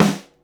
Tuned drums (G key) Free sound effects and audio clips
• Smooth Steel Snare Drum Sample G Key 347.wav
Royality free steel snare drum sound tuned to the G note. Loudest frequency: 1030Hz
smooth-steel-snare-drum-sample-g-key-347-rKO.wav